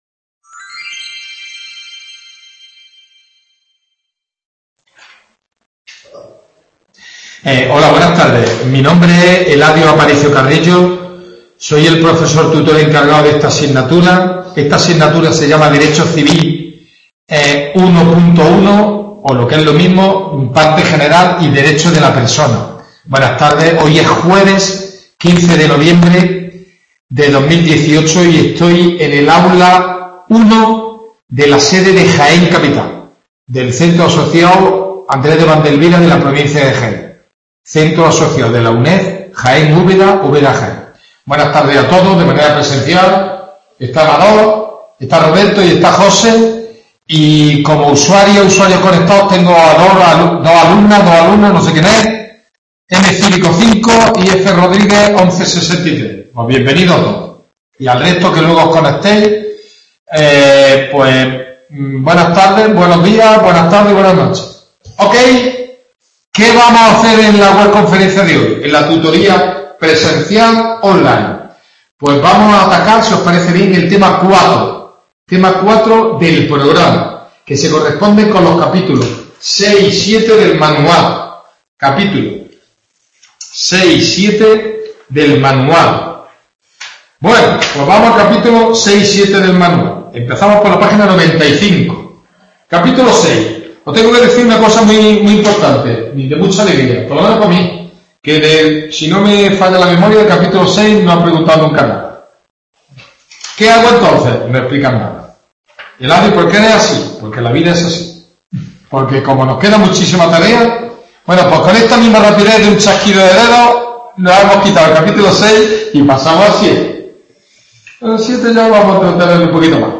WEBCONFERENCIA